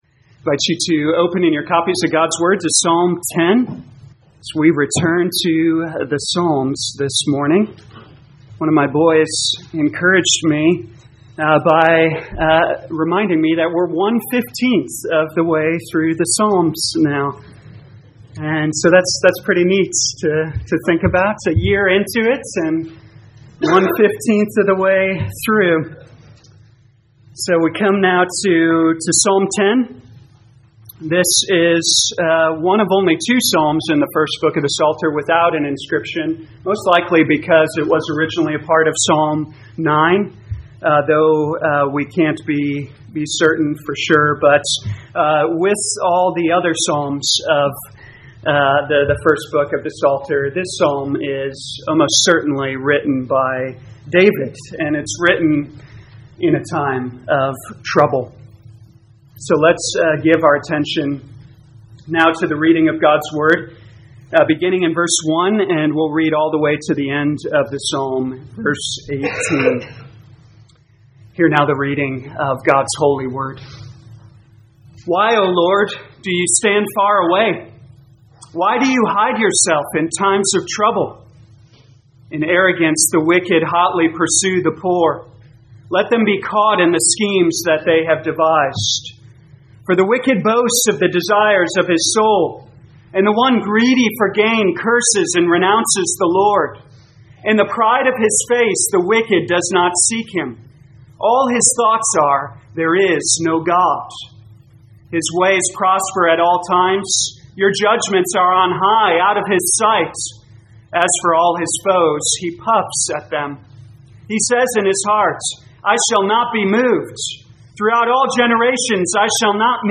2023 Psalms Morning Service Download: Audio Notes Bulletin All sermons are copyright by this church or the speaker indicated.